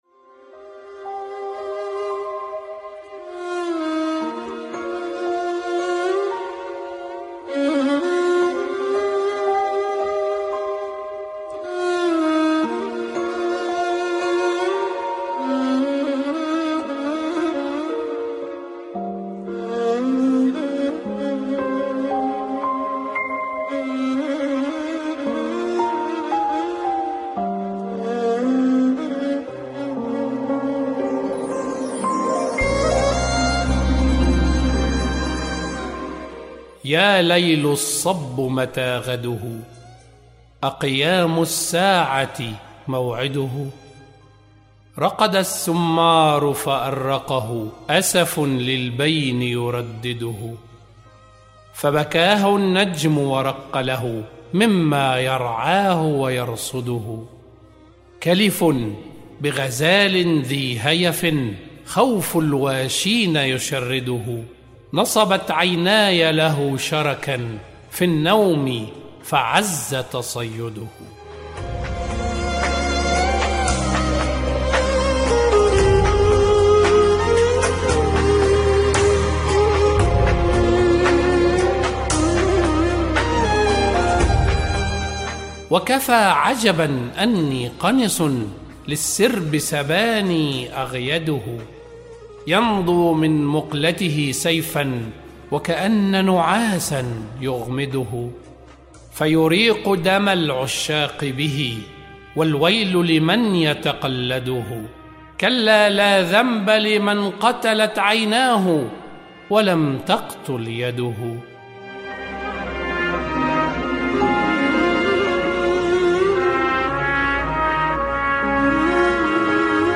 أعزائي المتابعين يسرنا أن نقدم لكم قصيدة ياليل الصب متى غده أقيام الساعة موعدهُ؟ للشاعر الحصري القيرواني